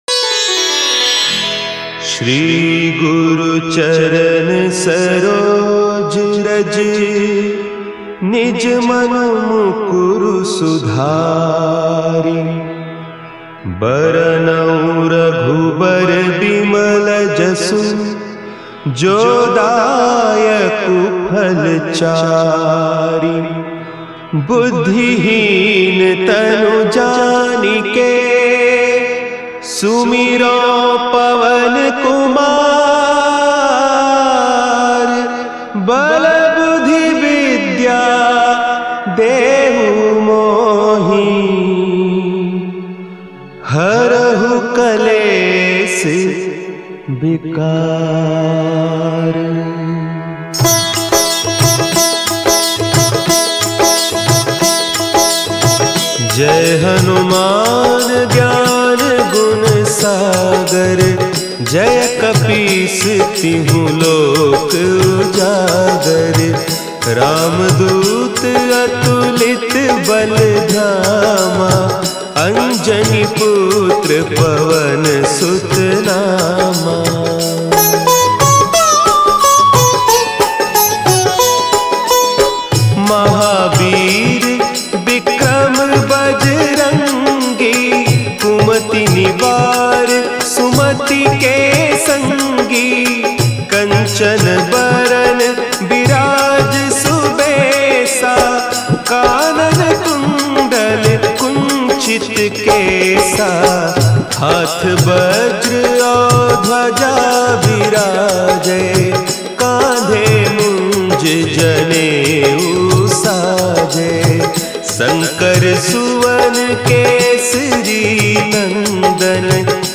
Releted Files Of Hanuman Bhajan Mp3 Songs